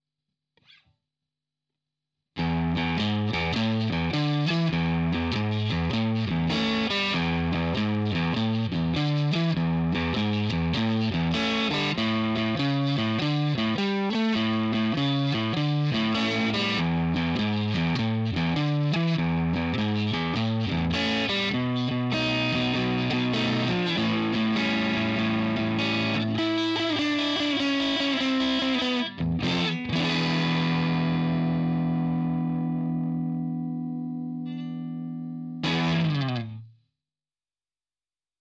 Nachvollziehen könnt Ihr das anhand folgender Files, die ich bei gleichen Einstellungen (einfach nur die Gitarre neu eingestöpselt) erstellt habe.
Chevy Strat
PU: Neck
Sound: dirty   Soundfile:
Chevy Neck Dirty.mp3